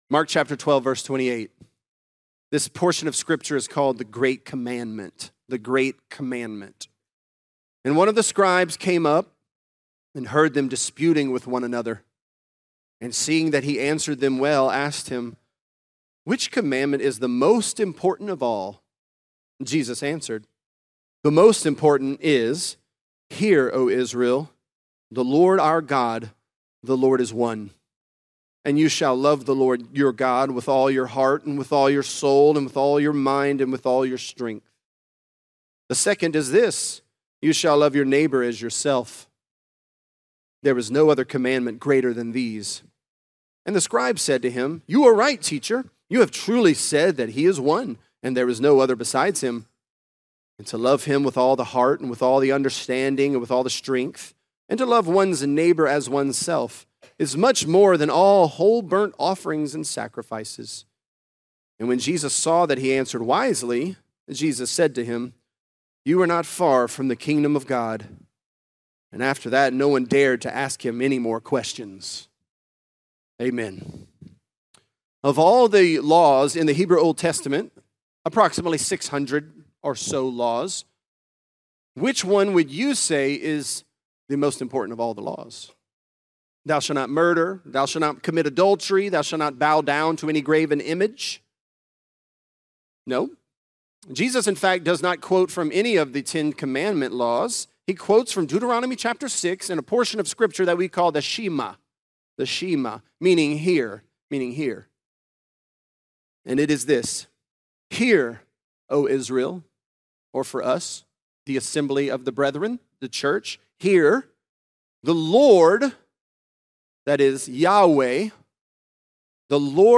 The Great Commandment | Lafayette - Sermon (Mark 12)